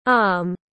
Arm /ɑːm/